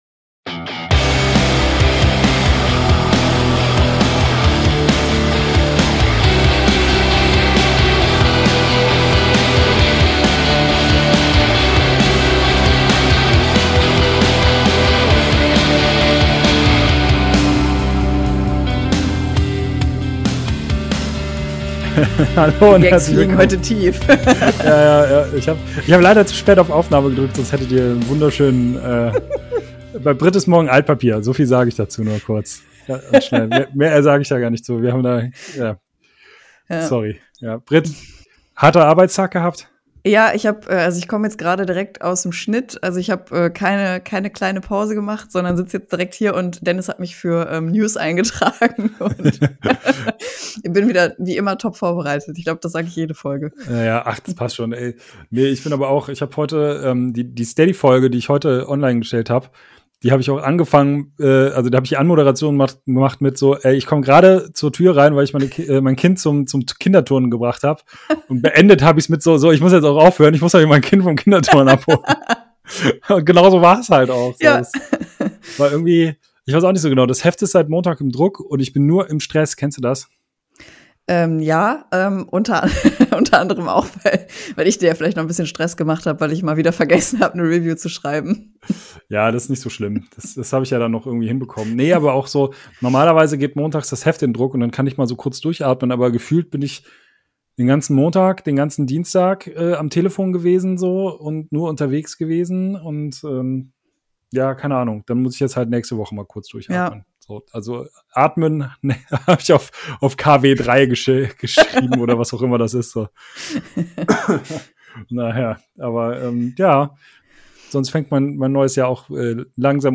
Interview ALARMSIGNAL